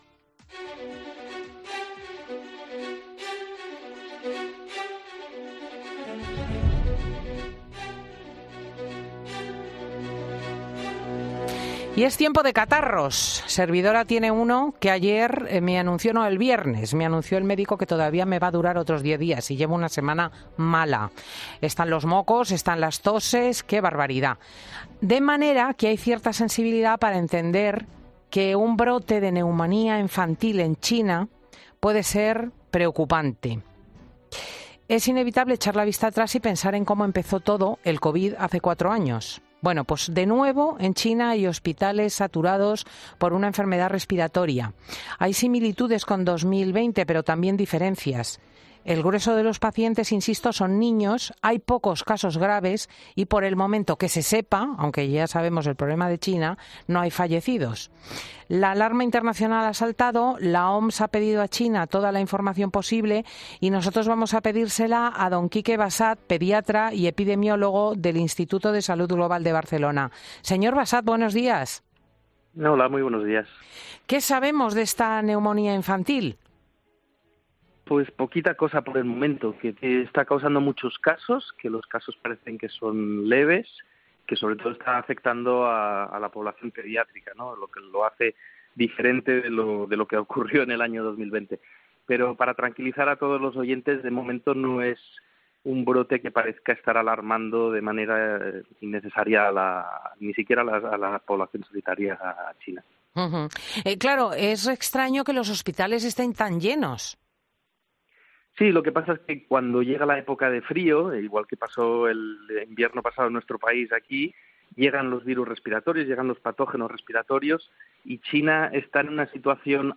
¿Debe preocuparnos en España el brote de neumonía infantil en China? Un virólogo da las claves de la infección